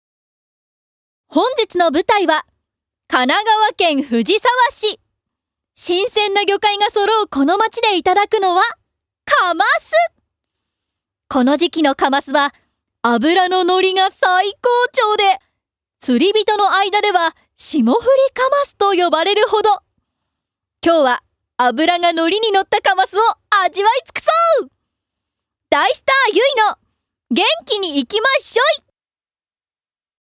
◆グルメ番組ナレーション◆